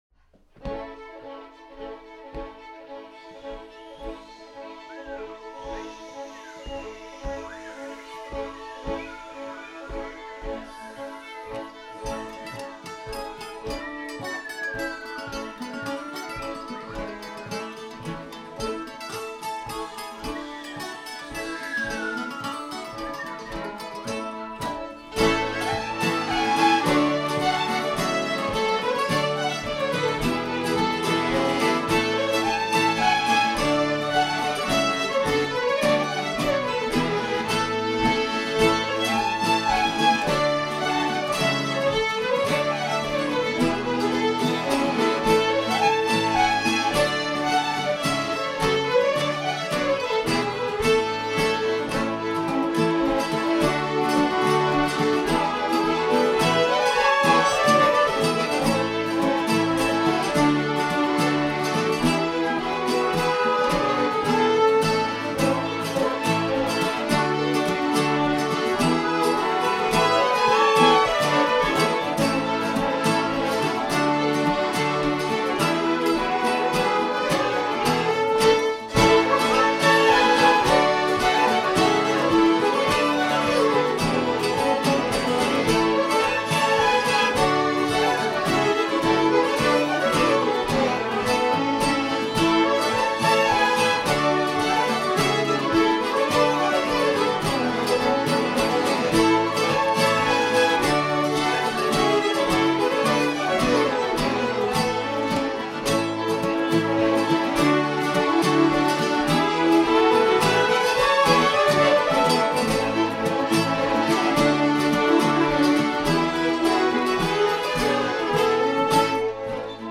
Inspelningar från rephelgen